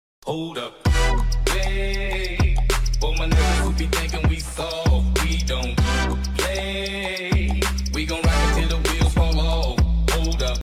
twitchhitbox-followdonation-sound-3_mZd2r4D.mp3